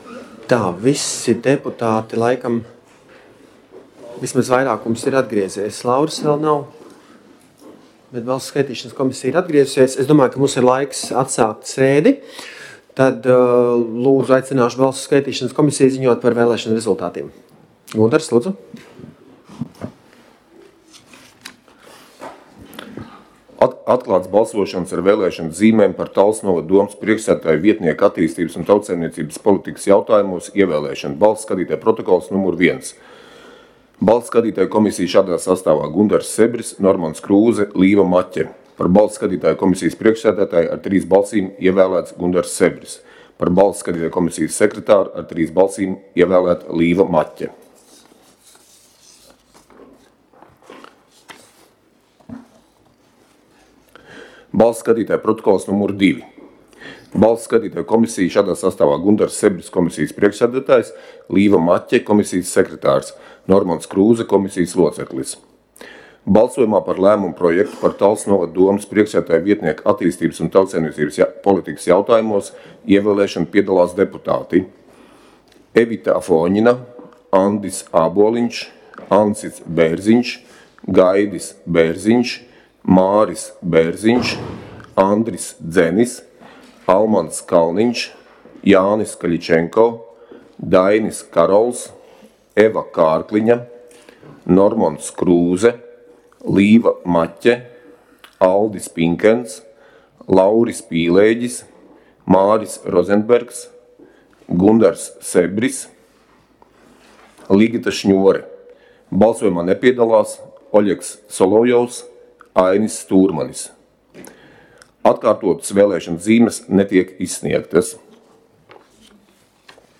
Talsu novada domes sēde Nr. 2